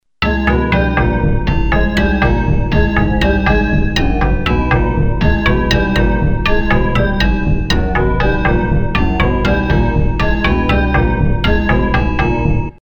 An interesting computer generated music composition.